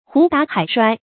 胡打海摔 hú dǎ hǎi shuāi
胡打海摔发音